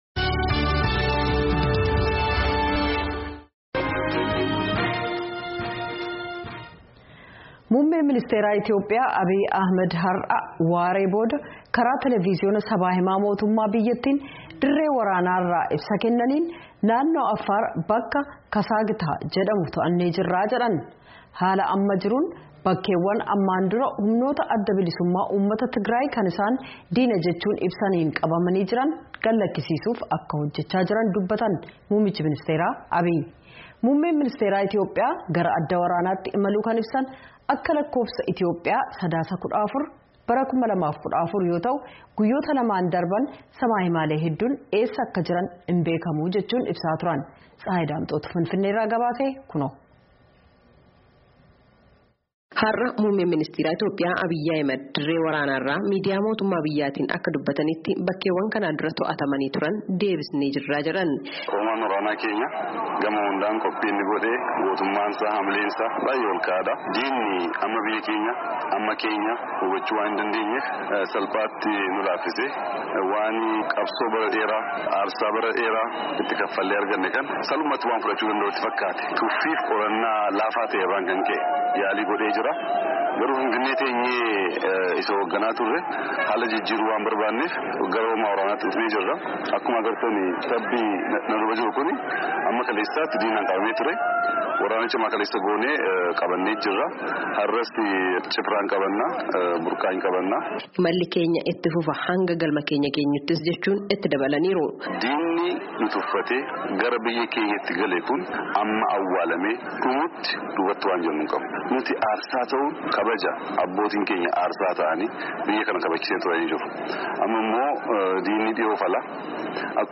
Finfinnee irraa gabaase.